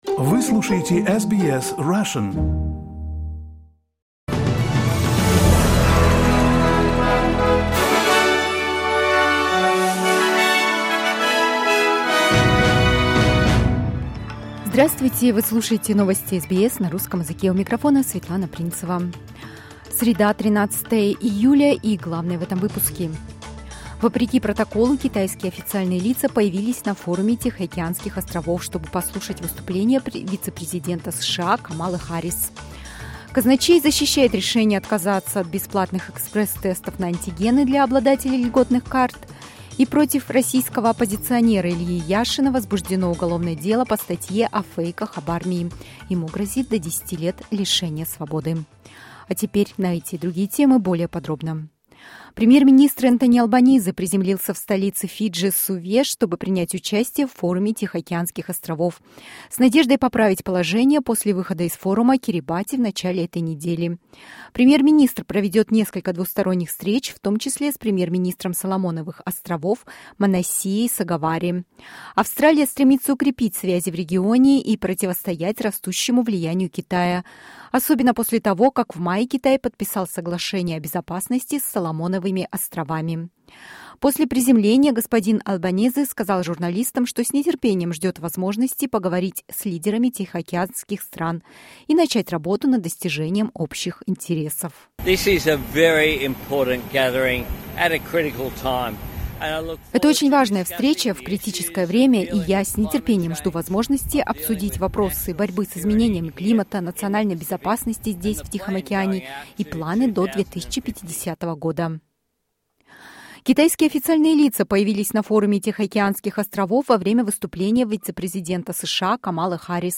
SBS News in Russian - 13.07.22